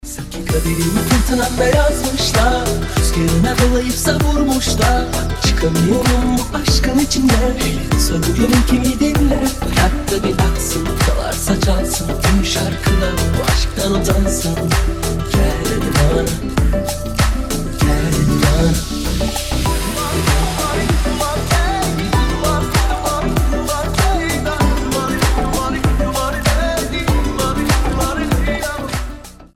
• Качество: 320, Stereo
мужской вокал
deep house
восточные
nu disco
Indie Dance